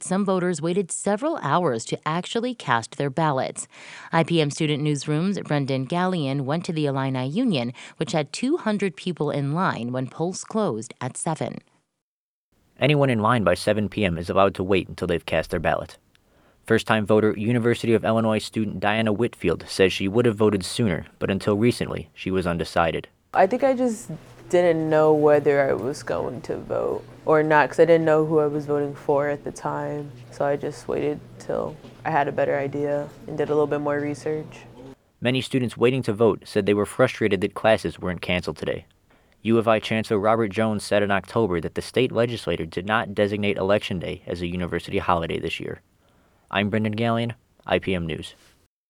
The Illinois Student Newsroom at IPM checked in with voters at polling locations on campus at the University of Illinois at Urbana-Champaign.